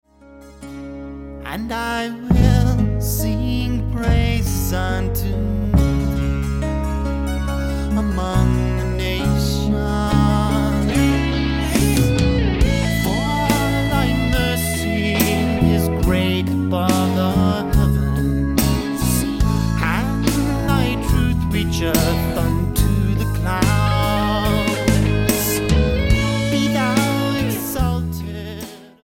STYLE: MOR / Soft Pop